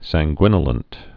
(săng-gwĭnə-lənt)